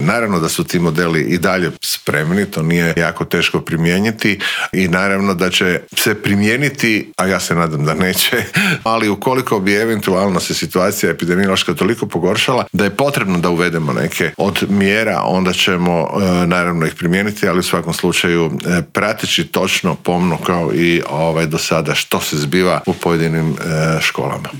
ZAGREB - U ponedjeljak će zvono označiti početak nove školske godine, a prije nego što se školarci vrate pred ploču, pred mikrofon Media servisa u Intervjuu tjedna stao je ministar znanosti i obrazovanja Radovan Fuchs. Otkrio nam je kako će izgledati nova školska godina, zašto je pao broj superodlikaša, do kud je stigla istraga u aferi 'Daj pet', ali i treba li uvesti malu maturu.